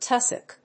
tus・sock /tˈʌsək/
• / tˈʌsək(米国英語)
• / ˈtʌs.ək(英国英語)